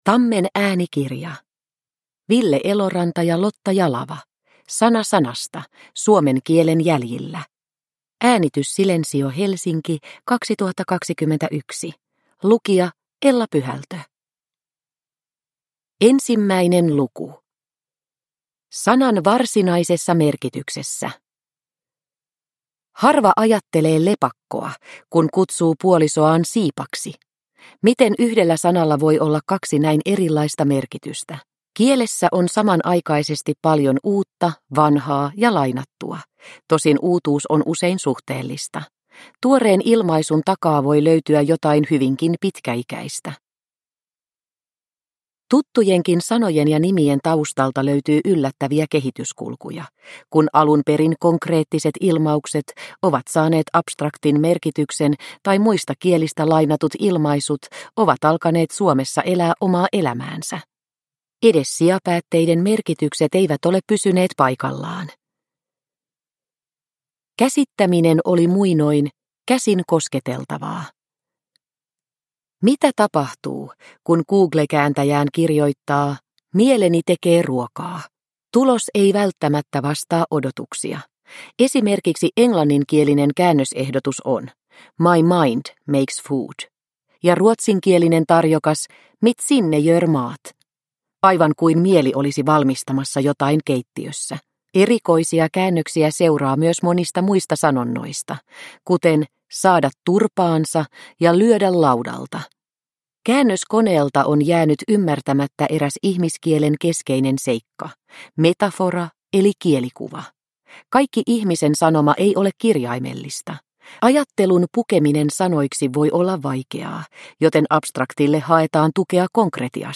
Sana sanasta – Ljudbok – Laddas ner